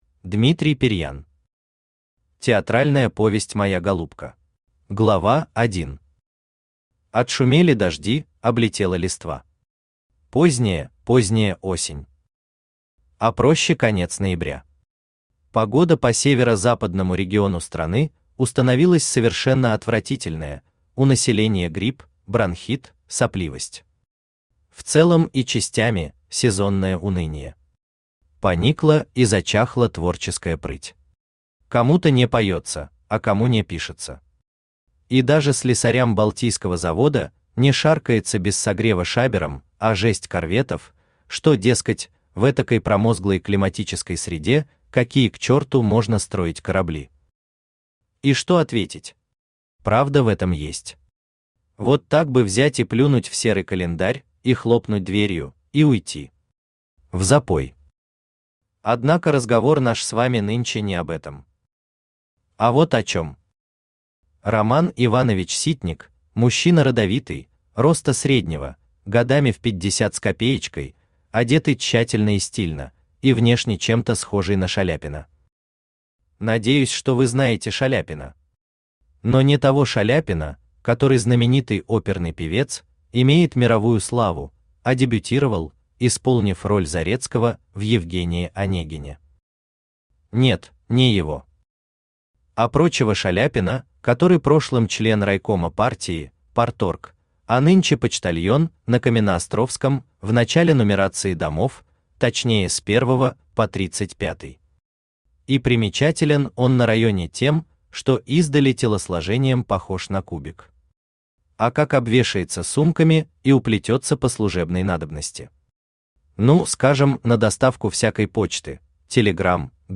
Aудиокнига Театральная повесть Моя голубка Автор Дмитрий Дмитриевич Пирьян Читает аудиокнигу Авточтец ЛитРес.